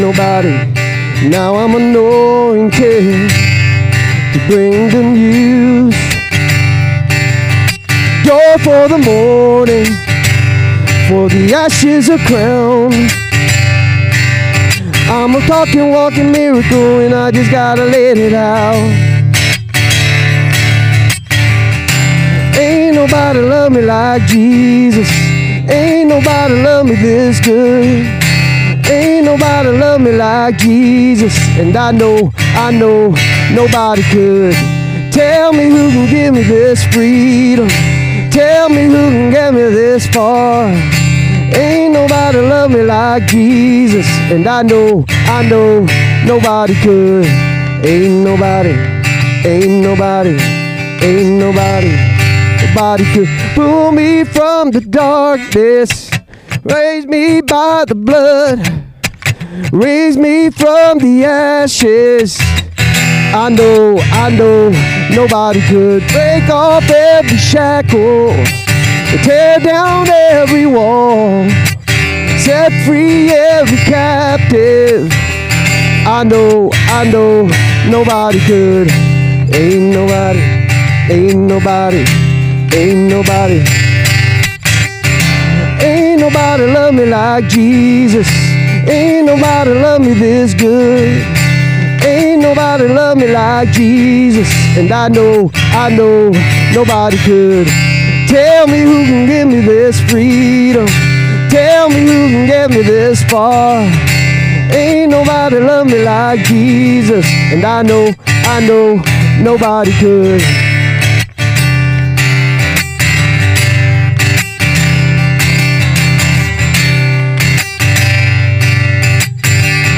Download Download Reference Acts 4:13, 19:11-29a; Philippians 2:12-13 Sermon Notes Click Here for Notes 250413.pdf SERMON DESCRITION I was driving to work and a person cut me off.